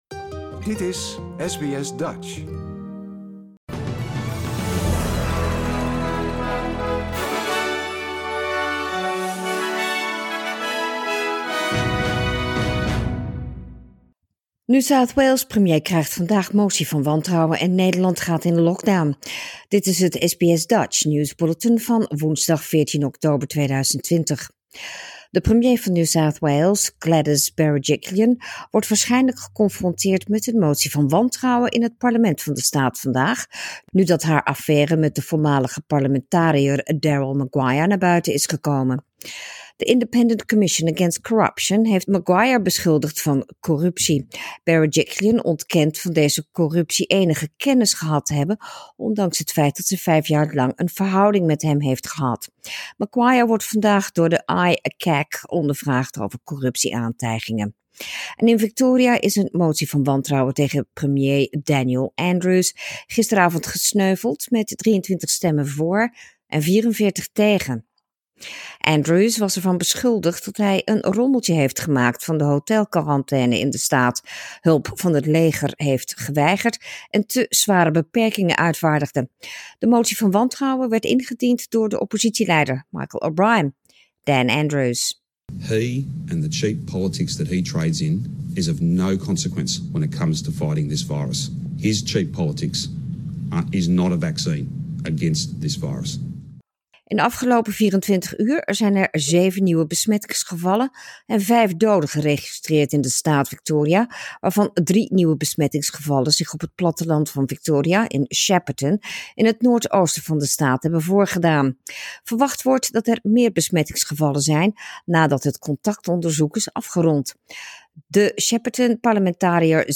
Nederlands/Australisch SBS Dutch nieuwsbulletin woensdag 14 oktober 2020